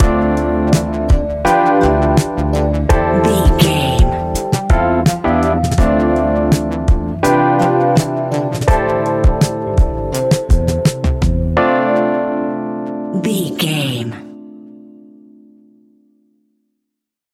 Ionian/Major
laid back
Lounge
sparse
new age
chilled electronica
ambient
atmospheric
instrumentals